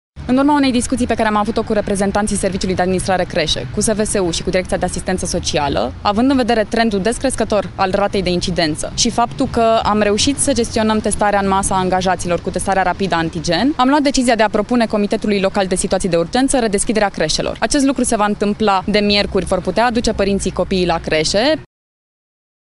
Viceprimarul municipiului Brașov, Flavia Boghiu: